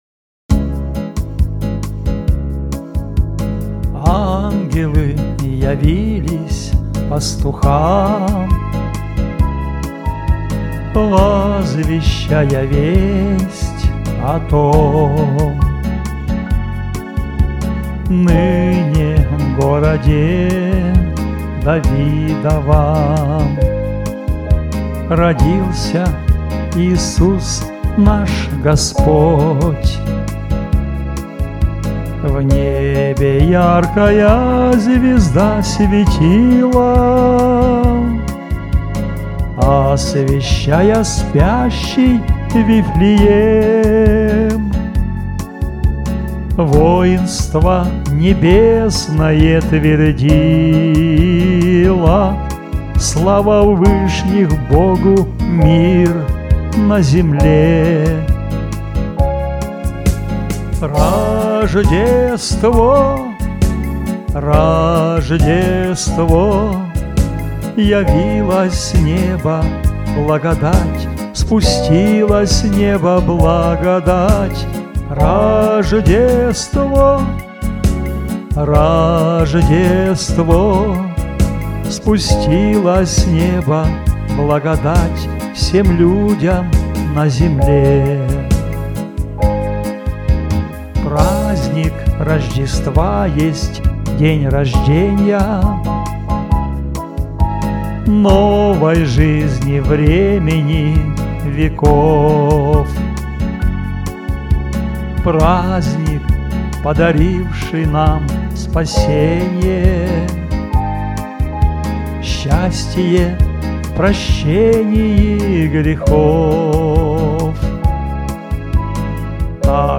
Теги: Христианские песни